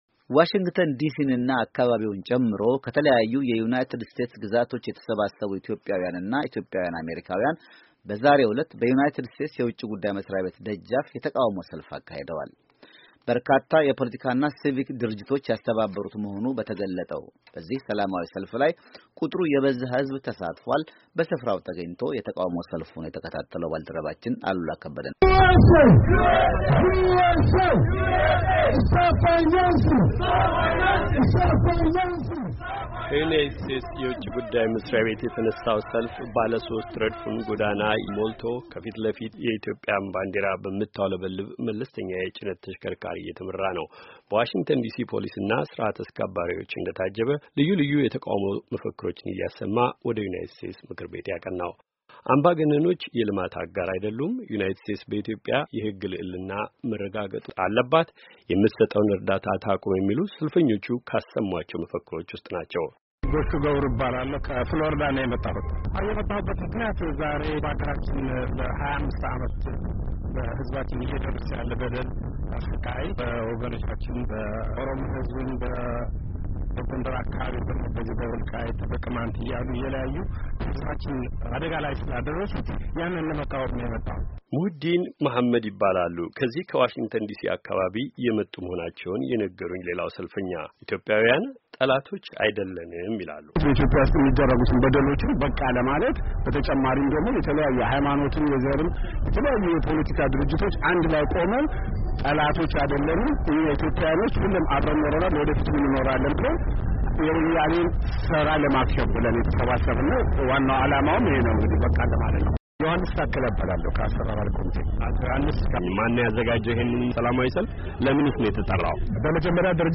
ዩናይትድ ስቴትስ በኢትዮጵያ የሕግ ልዕልና ይረጋገጥ ዘንድ መቆም አለባት።” የሚሉና የመሳሰሉ መፈክሮችን አሰምተዋል። ዘገባውን ለማዳመጥ ከዚህ በታች ያለውን ፋይል ይጫኑ።